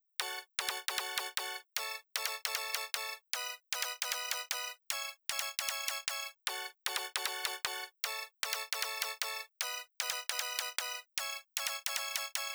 アップビート
アップビート、即ち「裏打ち」です。
パッドほどではないですが、音量が小さいため